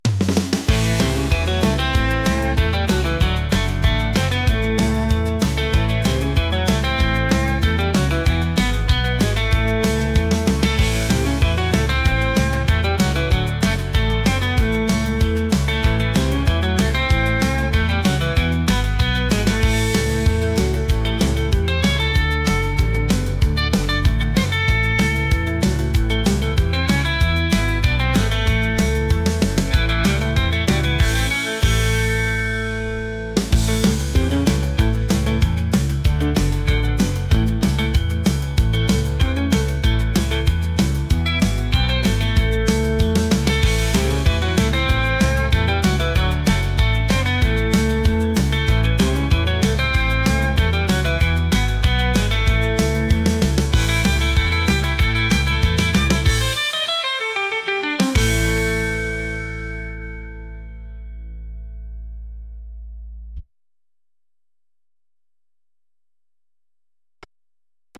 country | upbeat | catchy